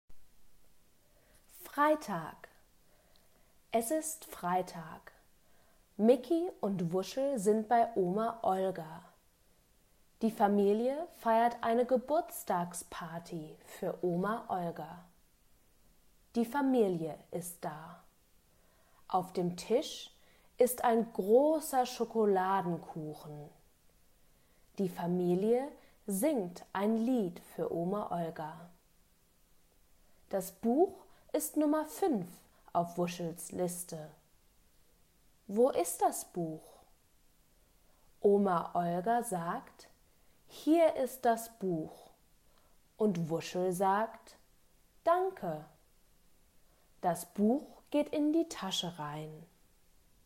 Gelesener Text: Begleitheft S.34(MP3, 804 KB)